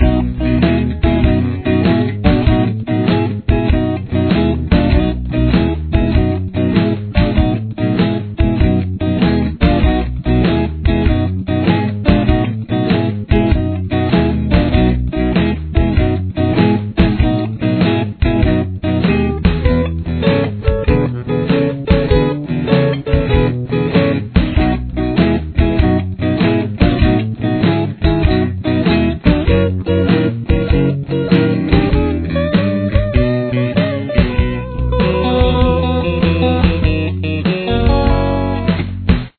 The main riff is compromised of two different guitar parts.
Here’s what both guitar parts sound like together: